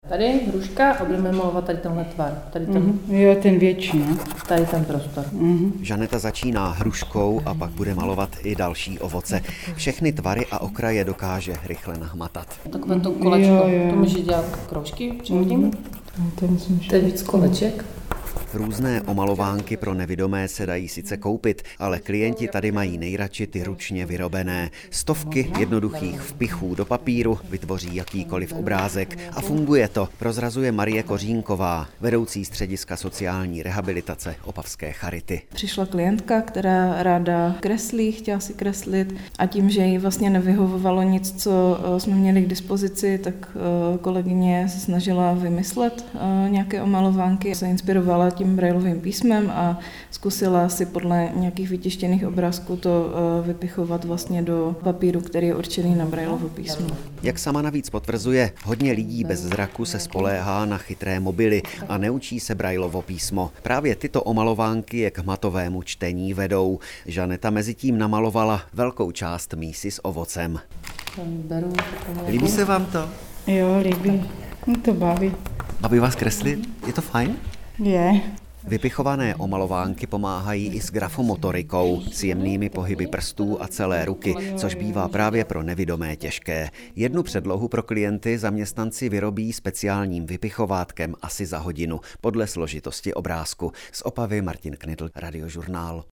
Charitní omalovánky pro nevidomé v reportáži Českého rozhlasu
Vyrábějí a používají je klienti Spojky a kromě zábavy přispívají také k rozvoji grafomotoriky. Reportáž o tom přinesl Český rozhlas Ostrava.